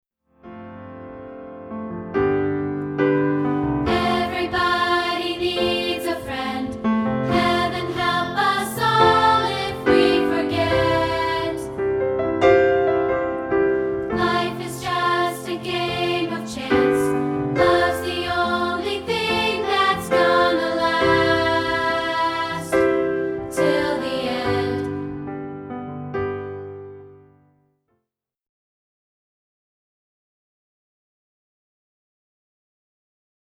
rehearsal tracks
which is the first segment of part 2, isolated.